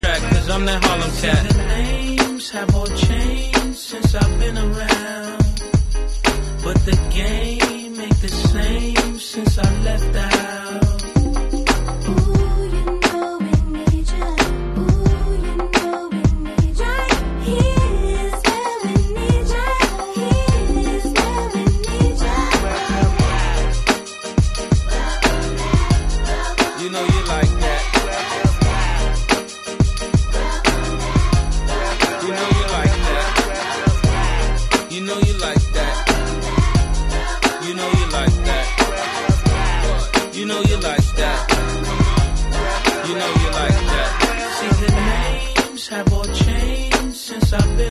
• Category Hip Hop